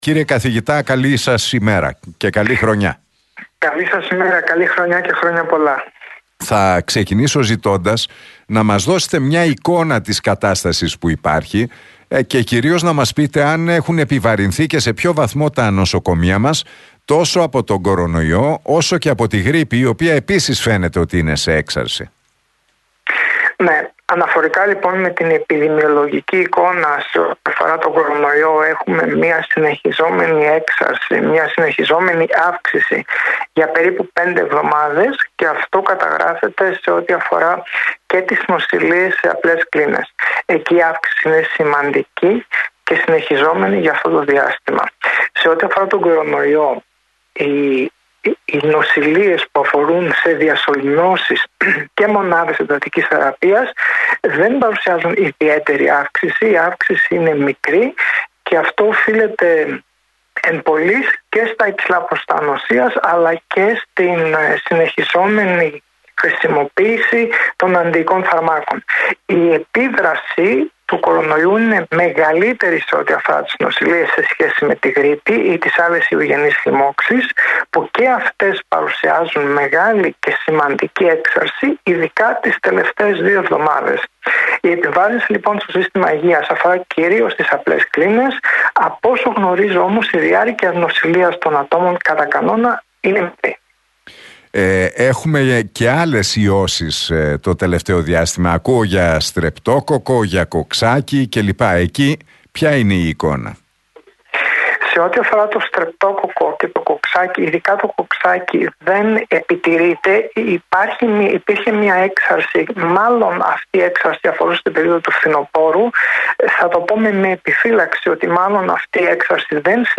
Την επιδημιολογική εικόνα όσον αφορά στον κορονοϊό, τη γρίπη και τις άλλες αναπνευστικές λοιμώξεις περιέγραψε στον Realfm 97,8 και την εκπομπή του Νίκου Χατζηνικολάου ο Καθηγητής Επιδημιολογίας του Πανεπιστημίου Αθηνών, Δημήτρης Παρασκευής.